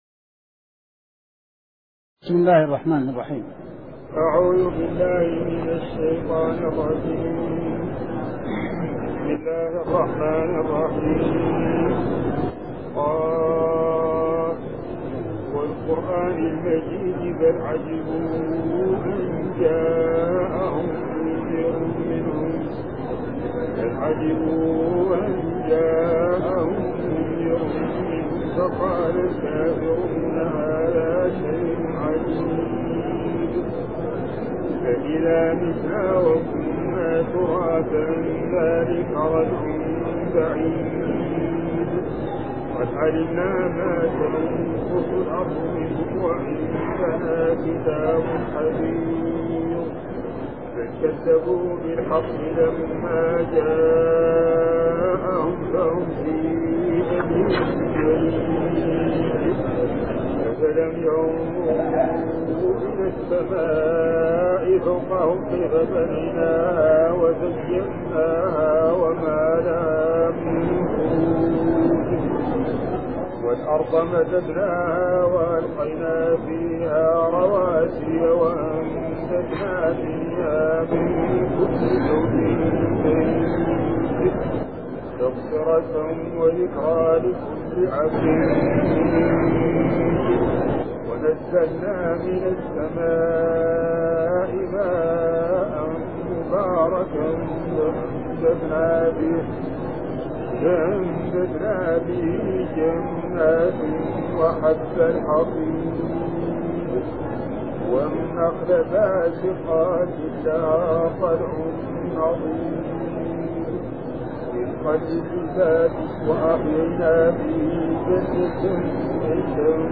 من دروس الحرم المدنى الشريف